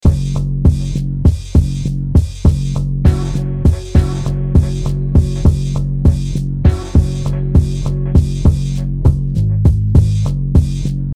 Loop All Powerful Funk Beat Sound Button - Free Download & Play